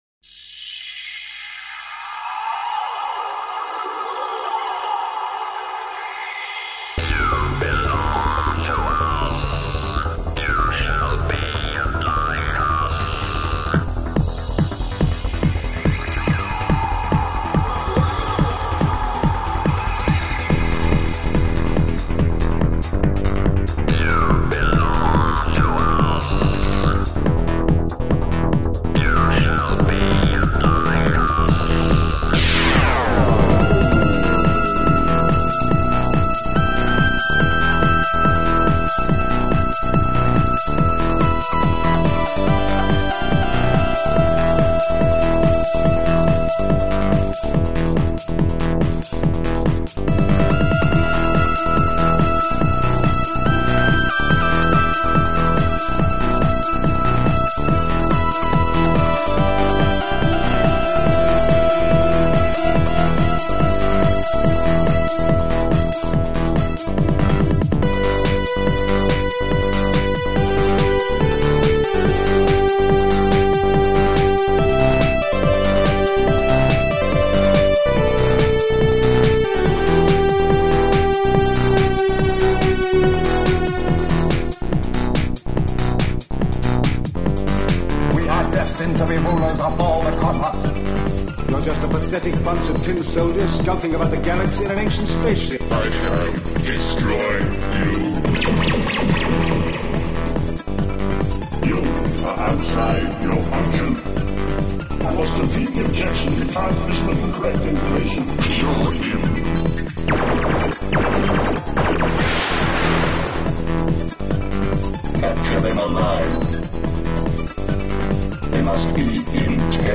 An experiment in using dance loops, soundbites